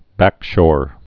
(băkshôr)